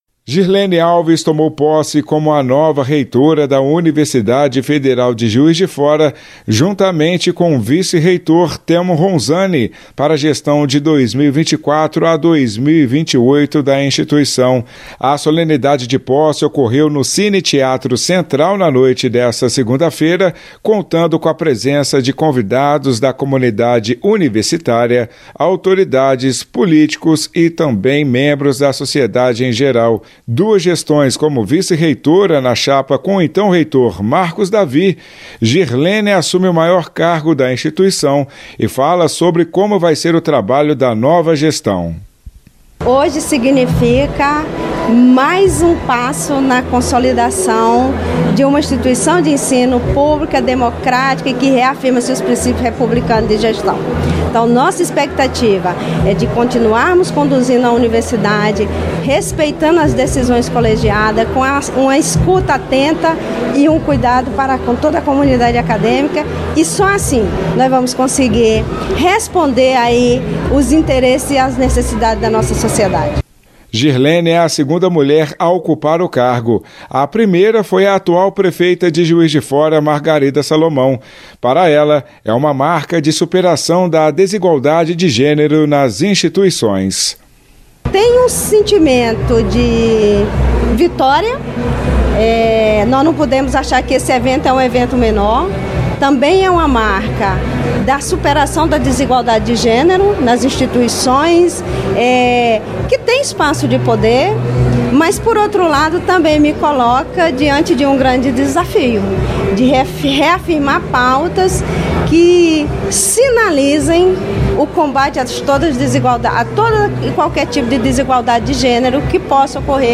Ouça a entrevista dela, do vice-reitor e de outras autoridades